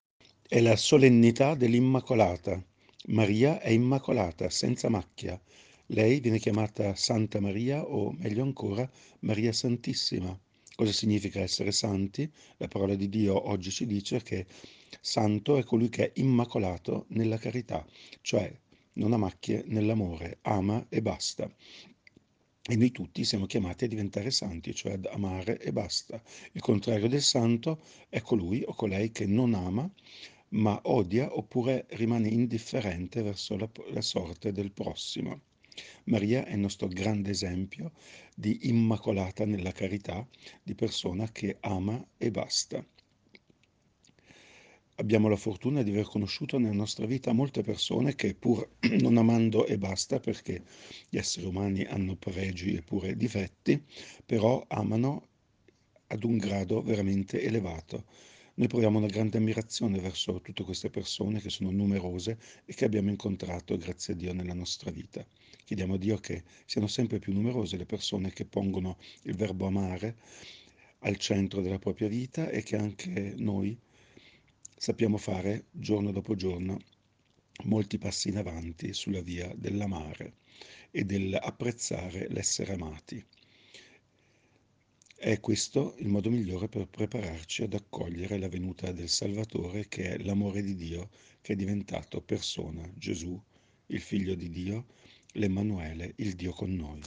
Meditazione Immacolata Concezione 8 Dicembre 2023 – Parrocchia di San Giuseppe Rovereto
Meditazione-Immacolata-2023.mp3